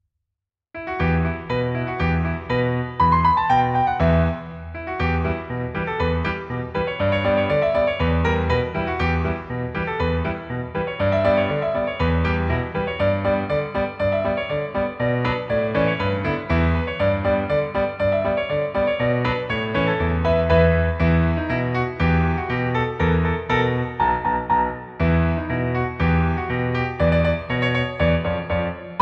• 🎹 Instrument: Piano Solo
• 🎼 Key: E Minor
• 🎶 Genre: Video Game, Soundtrack, TV/Film
energetic and fun piano solo arrangement